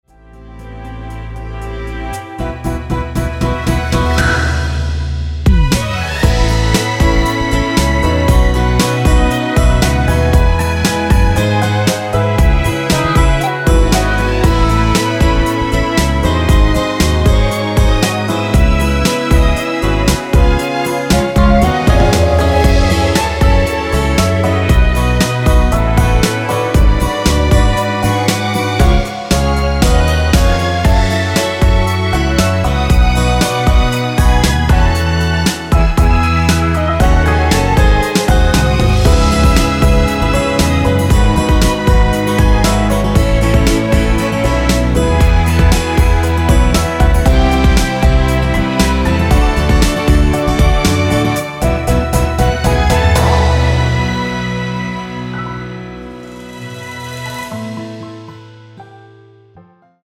원키에서(+4)올린 (1절+후렴)으로 진행되는 MR입니다.
C#m
◈ 곡명 옆 (-1)은 반음 내림, (+1)은 반음 올림 입니다.
앞부분30초, 뒷부분30초씩 편집해서 올려 드리고 있습니다.
중간에 음이 끈어지고 다시 나오는 이유는